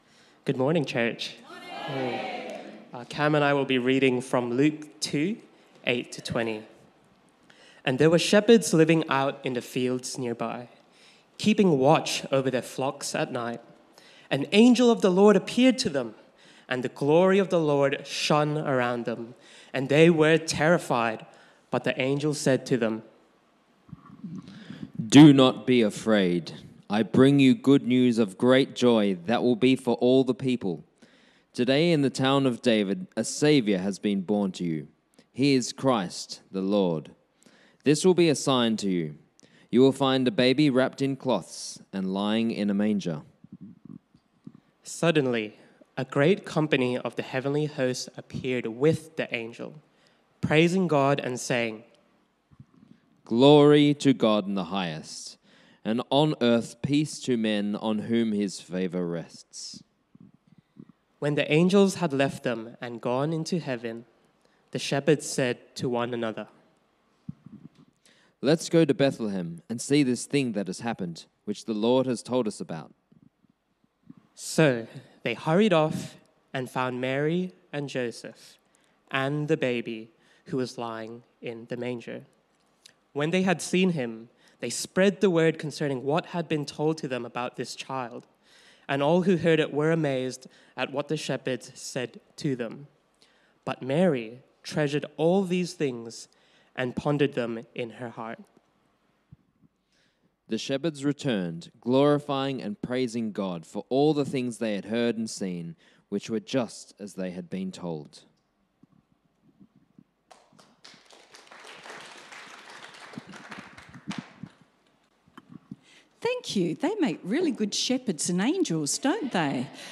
Sermon Transcript Luke 2:8-20 And there were shepherds living out in the fields nearby, keeping watch over their flocks at night.